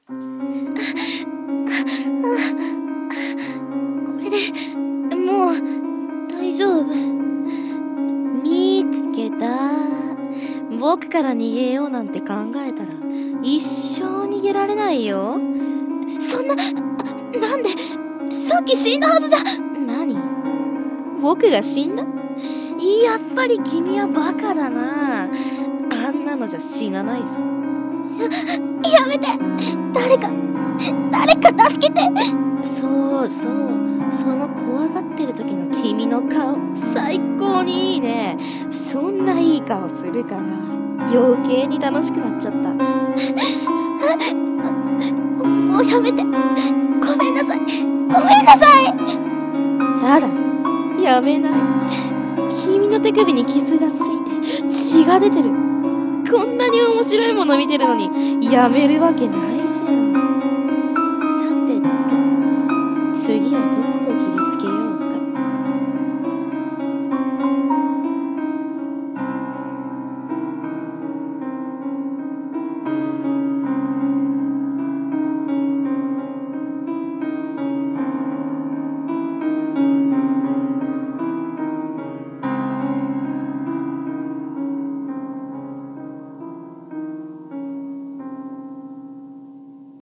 (ホラー声劇)